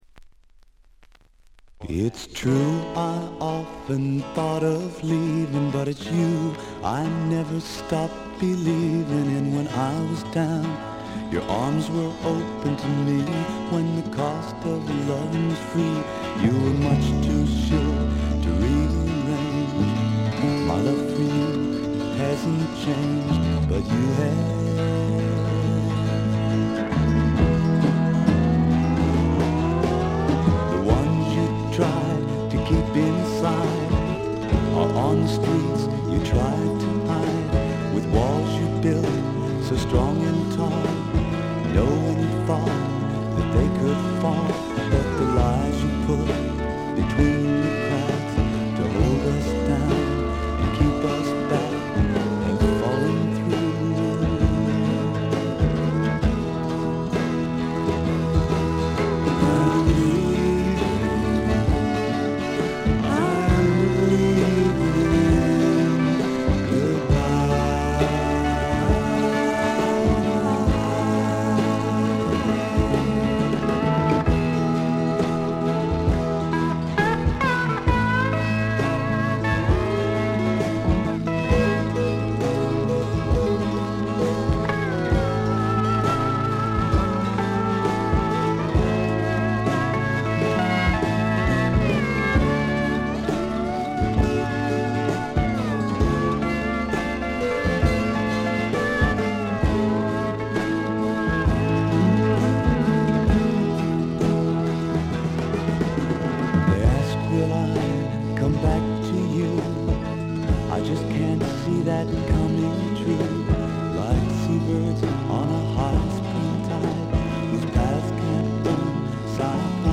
軽微なチリプチが少し。散発的なプツ音が少し。
試聴曲は現品からの取り込み音源です。